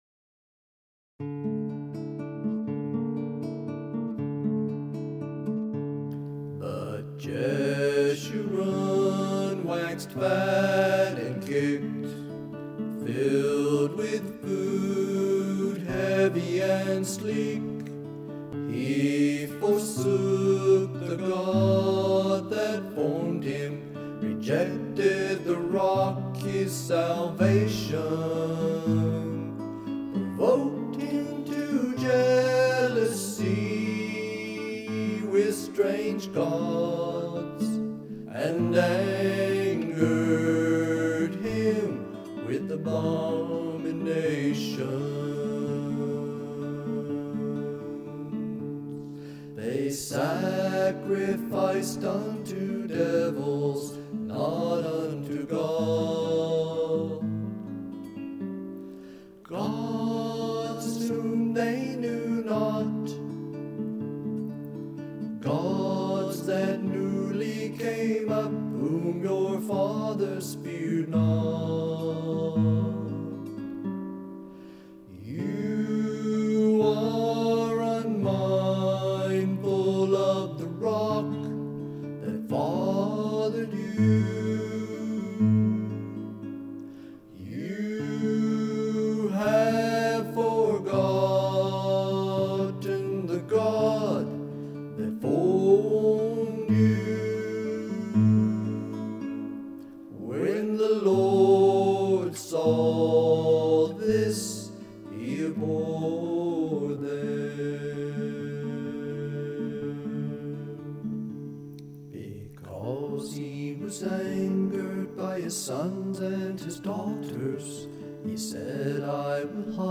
7:00 am to 8:00 am – Morning Worship, Prayer, Breaking of Bread – Learning “Song of Moses”